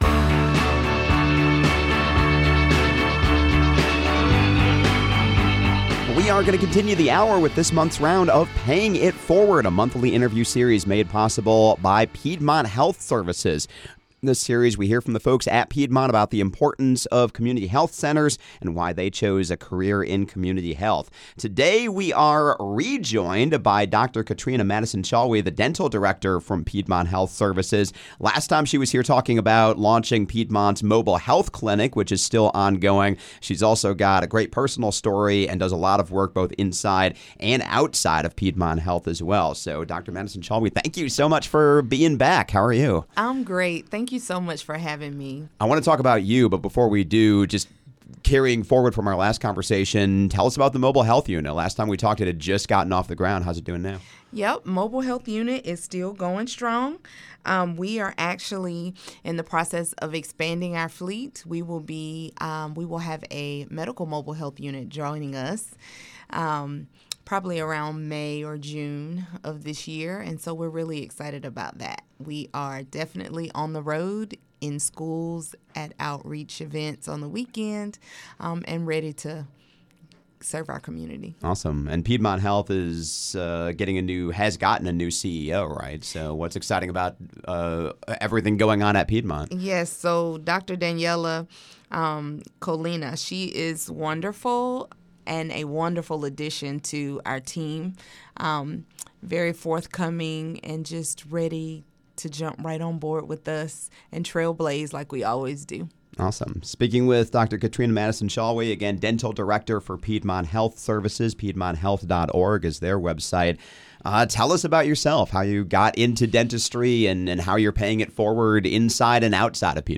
Welcome to “Paying it Forward,” a monthly interview series made possible by Piedmont Health. In this series, we hear from the folks at Piedmont about the importance of community health centers – and why they chose a career in community health.